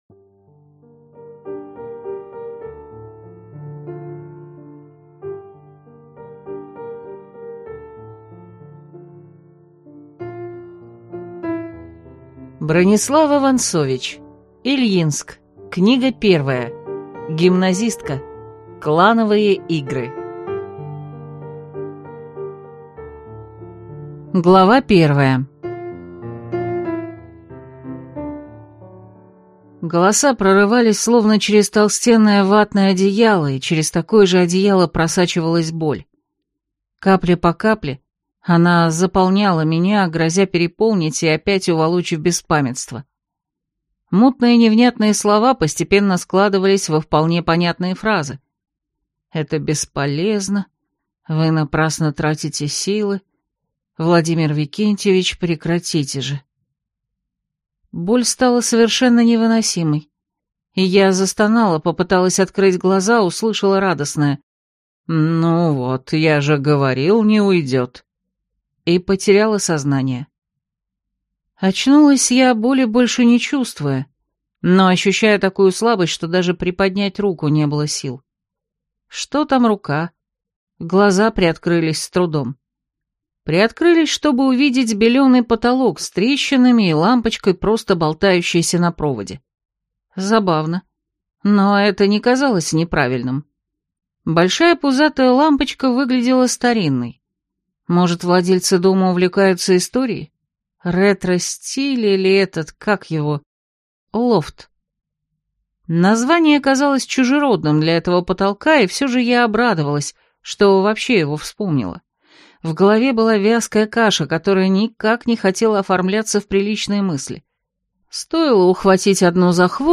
Аудиокнига Гимназистка. Клановые игры | Библиотека аудиокниг